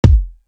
Different Worlds Kick.wav